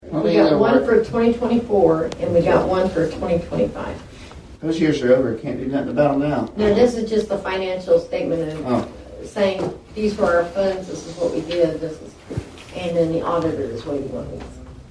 The Board of Nowata County Commissioners held a regularly scheduled meeting at the Nowata County Annex.
County Clerk Kay Spurgeon and Vice-Chairman Brandon Wesson discuss the purpose of the reports.